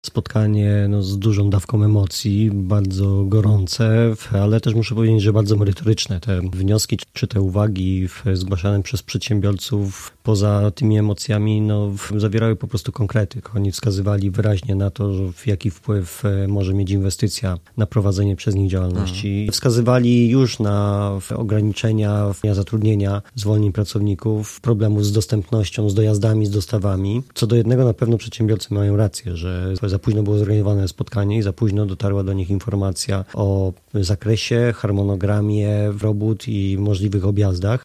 W spotkaniu z przedsiębiorcami uczestniczył wiceprezydent Jacek Szymankiewicz: